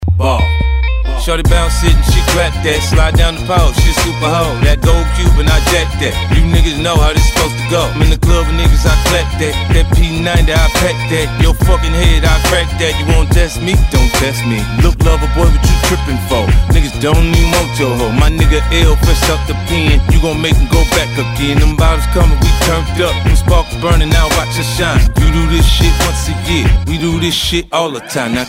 Категория: Рэп рингтоны